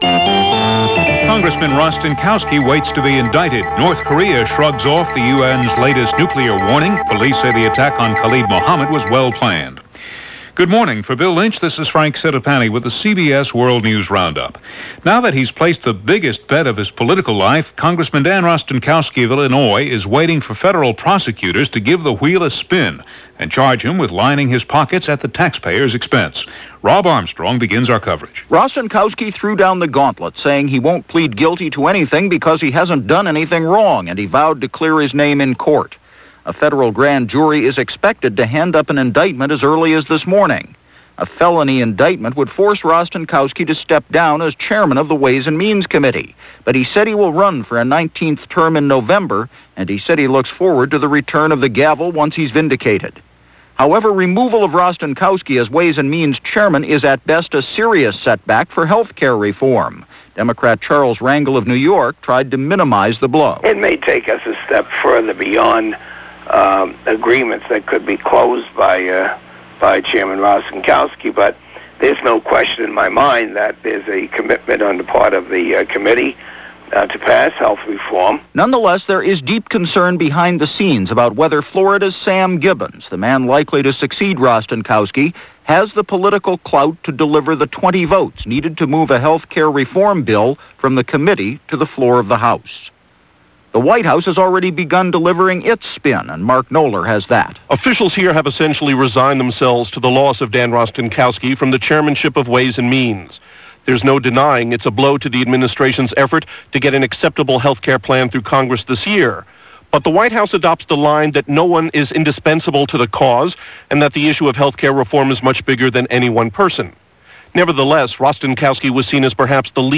And while shoes are waiting to drop on Capitol Hill over Dan Rostenkowski, that’s just a small slice of what happened, this May 31st in 1994 as reported by The CBS World News Roundup.